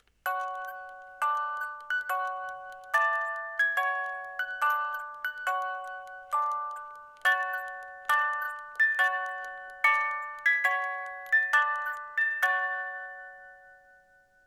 Spieluhr mit dem Trauermarsch (Marche funèbre) von F. Chopin
die Spieluhr besitzt ein Qualitätsspielwerk mit 18 Zungen
Der Ton dieser Spieluhren ist klar, warm und obertonreich und wird durch Kurbeln eines Qualitätsspielwerks erzeugt.